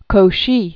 (kō-shē), Baron Augustin Louis 1789-1857.